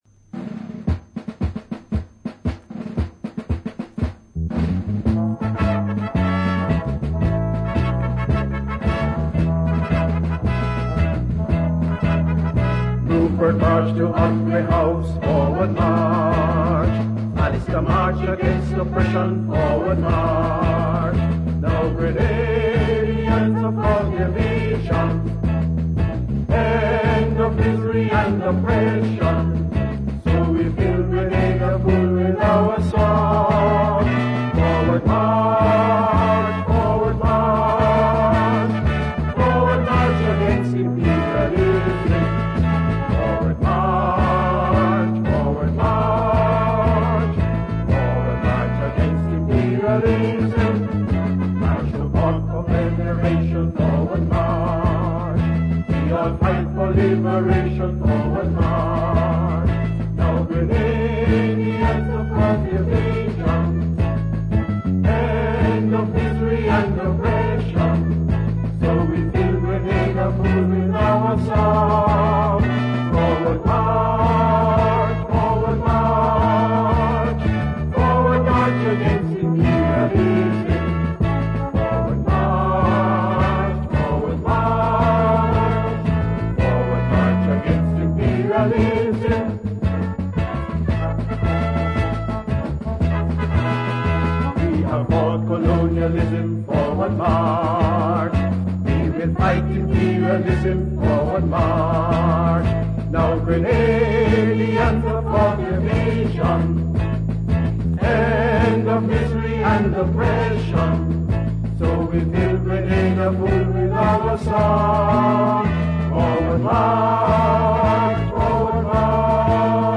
Forward March,” the anthem of the Grenada Revolution: